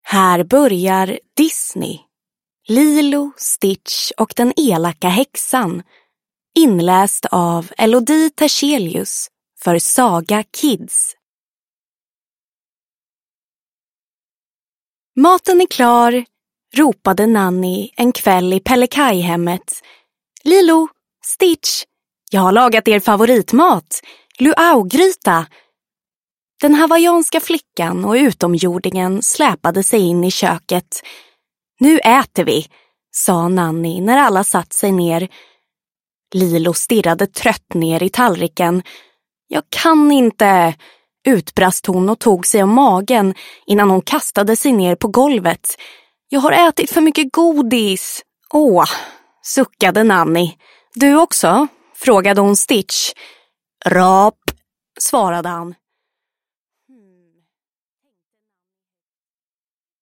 Lilo, Stitch och den elaka häxan – Ljudbok